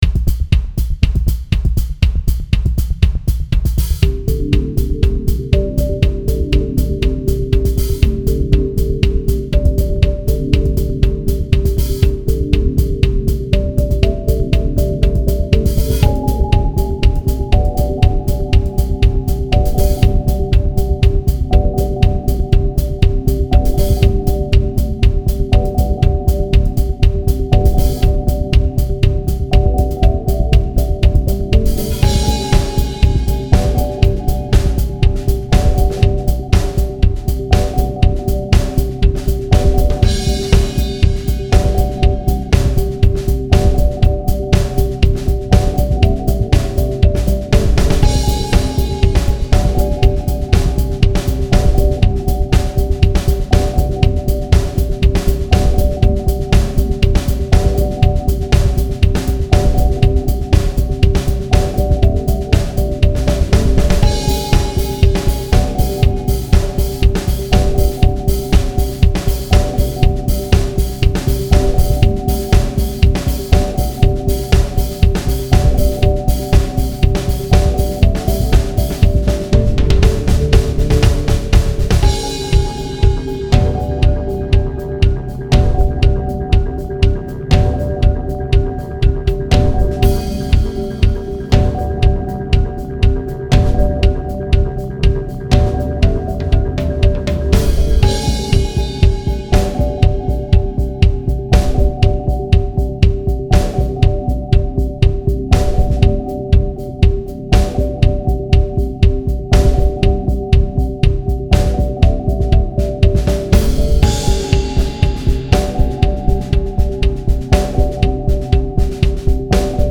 THE PREVIOUS VERSIONS ALBUM WITH ONLY ELECTRONIC SOUND